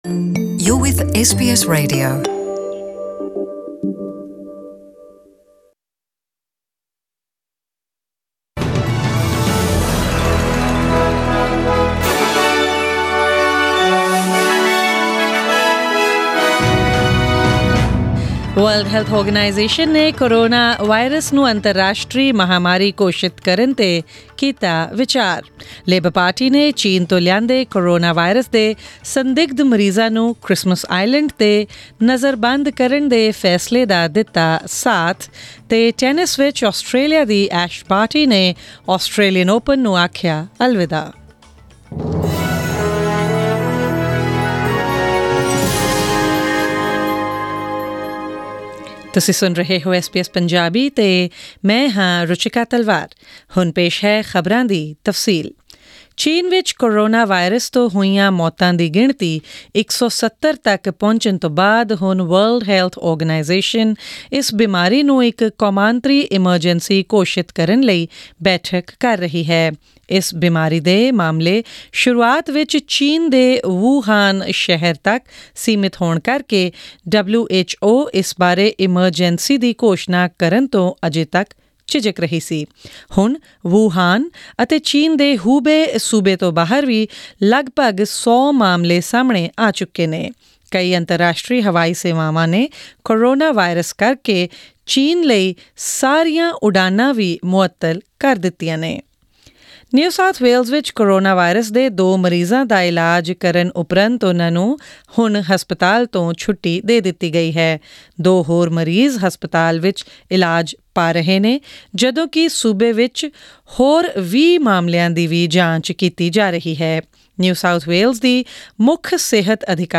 Australian News in Punjabi: 30 January 2020